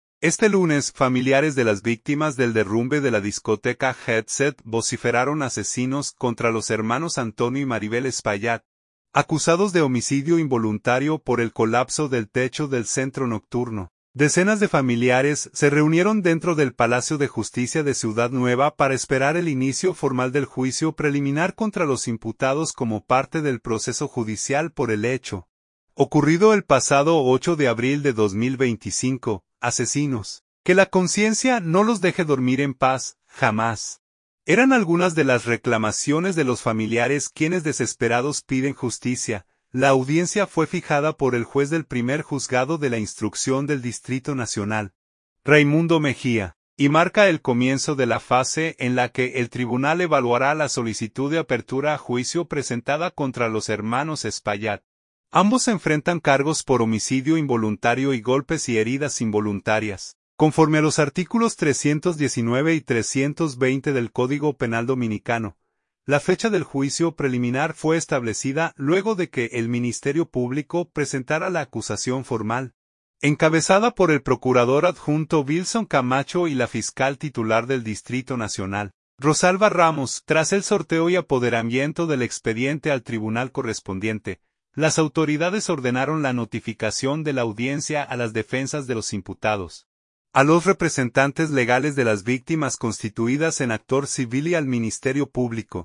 “Asesinos, la conciencia no los dejará dormir jamás”, vociferan familiares de las víctimas del Jet Set
Decenas de familiares se reunieron dentro del Palacio de Justicia de Ciudad Nueva  para esperar el inicio formal del juicio preliminar contra los imputados como parte del proceso judicial por el hecho ocurrido el pasado 8 de abril de 2025.
“Asesinos, que la conciencia no los deje dormir en paz, jamás”, eran algunas de las reclamaciones de los familiares quienes desesperados piden justicia.